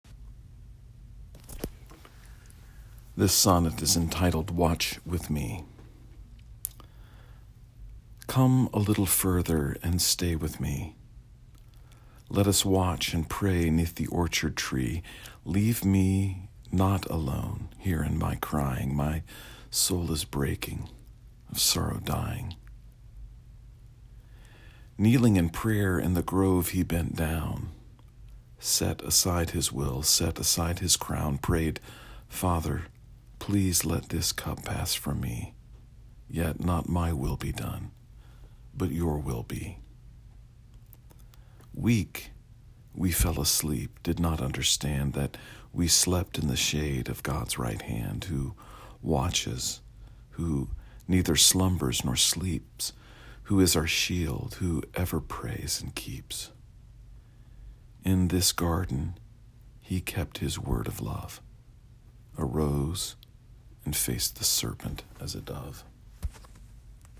You may listen to me read the poem via the player below.